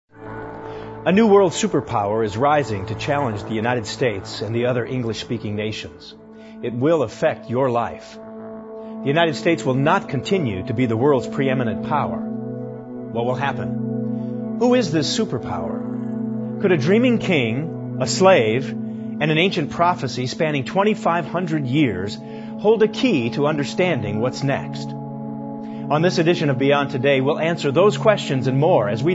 Beyond Today Television Program